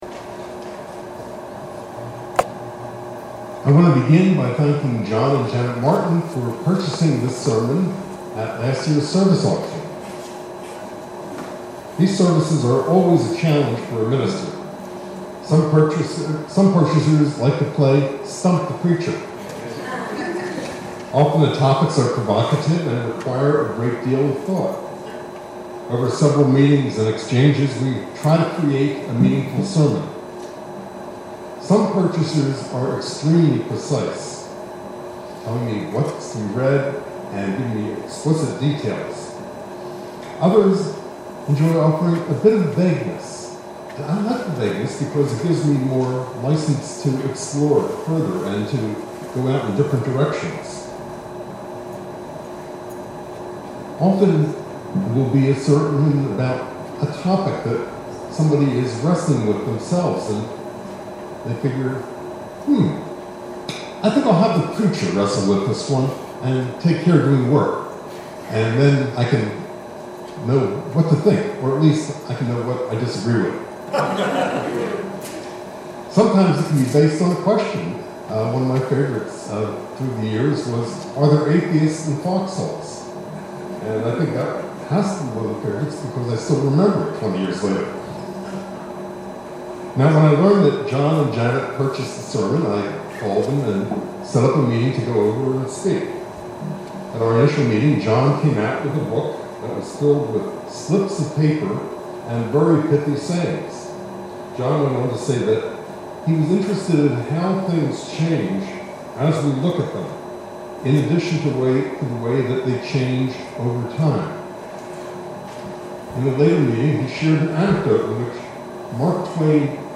Pablo Picasso – Sermons & Newsletter – UU Church of Rutland